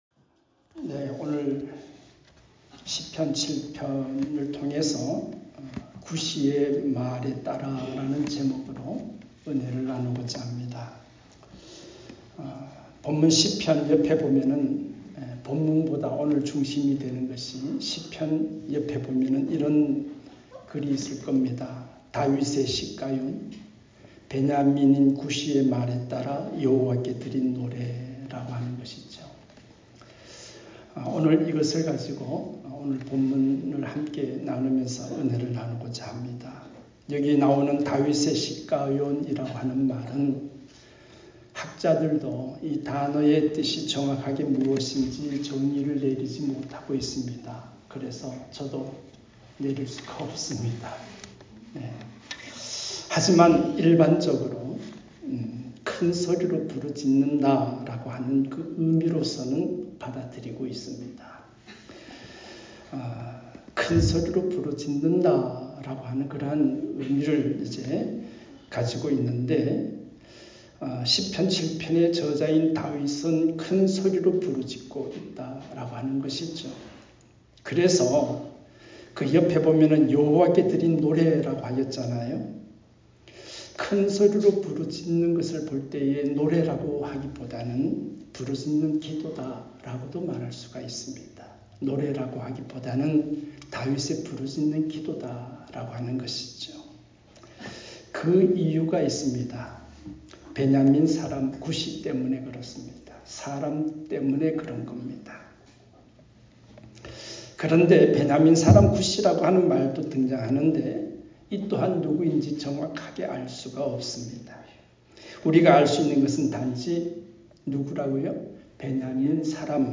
‘구시의 말’에 따라 ( 시편7:1-11 ) 말씀